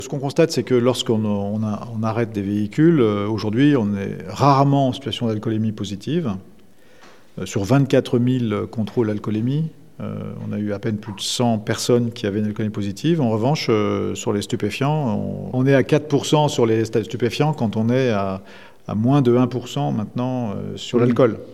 Philippe Castanet, prefet de la Lozère.